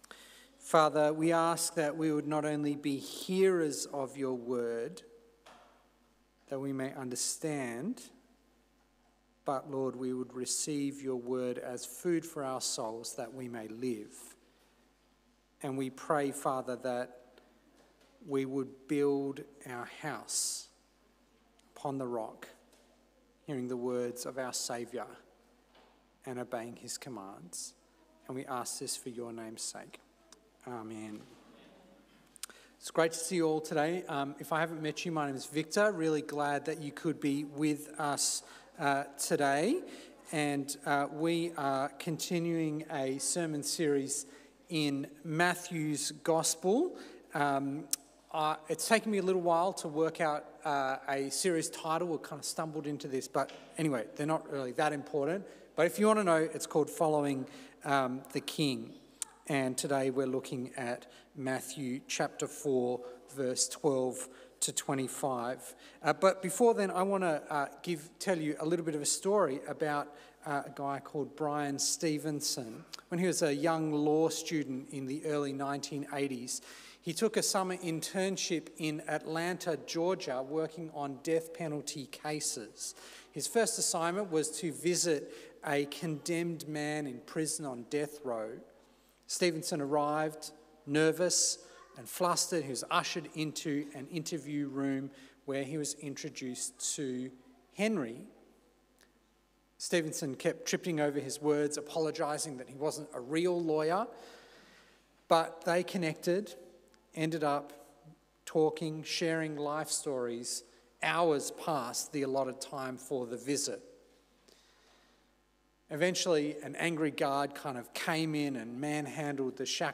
2026 The Kingdom is Here Preacher